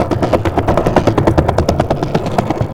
skateloop.ogg